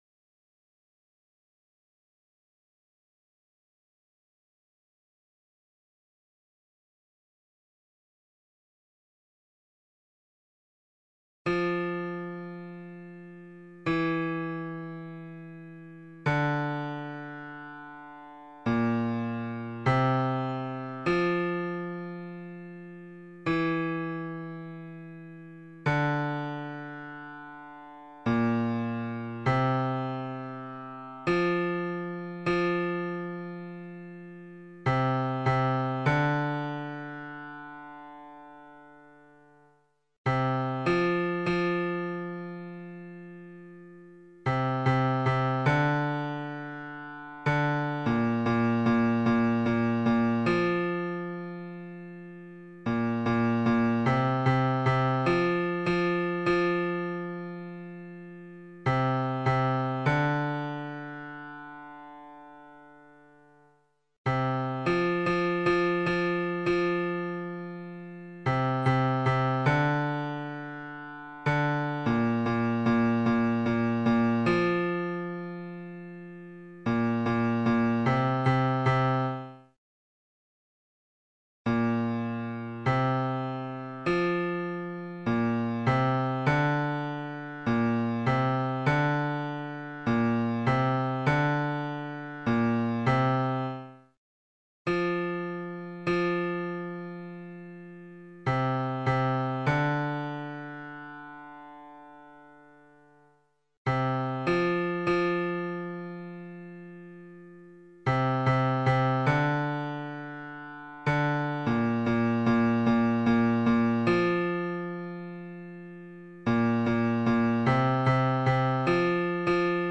Information and practice vocal scores & mp3's for the